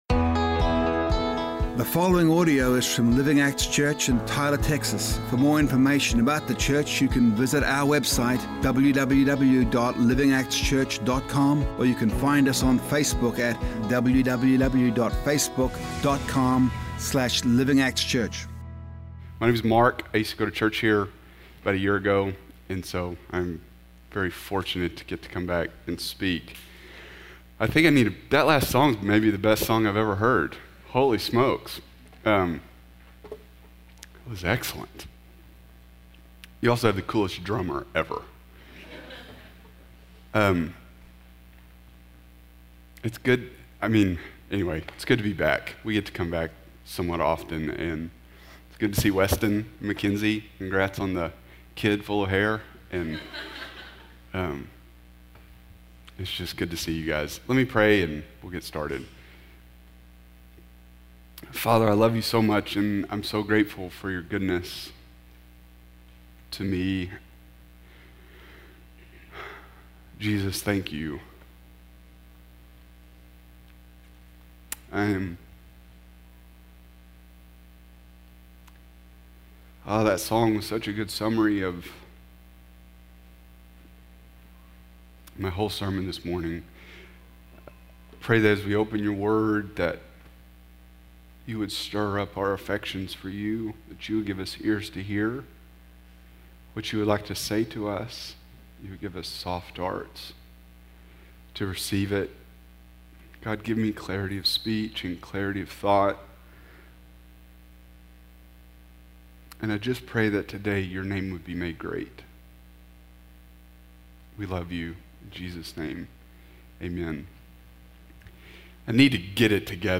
A message from the series "Knowing Our God."